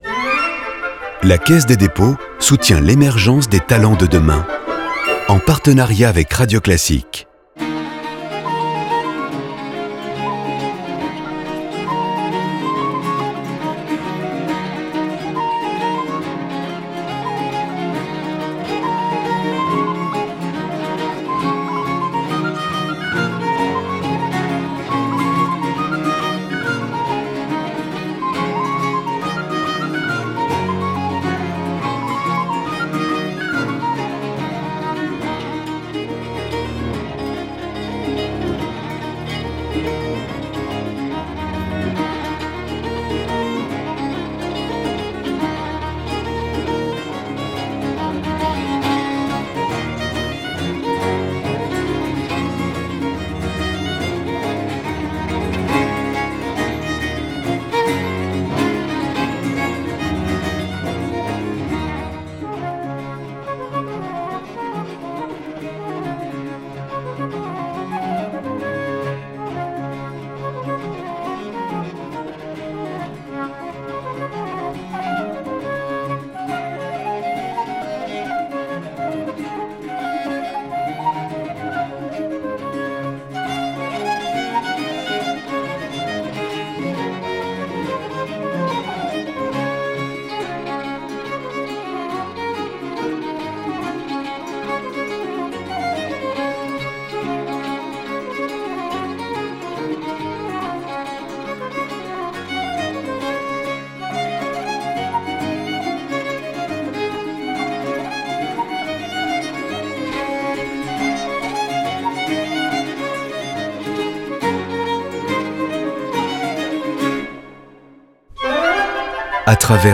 Jigs